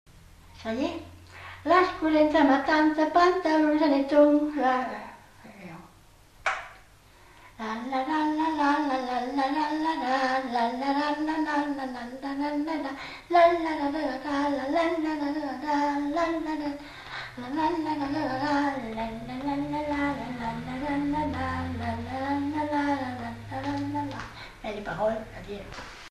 Aire culturelle : Gabardan
Genre : chant
Effectif : 1
Type de voix : voix de femme
Production du son : fredonné
Danse : rondeau
Notes consultables : Commence à chanter un vers, puis poursuit en fredonnant.